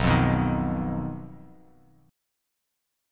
BMb5 chord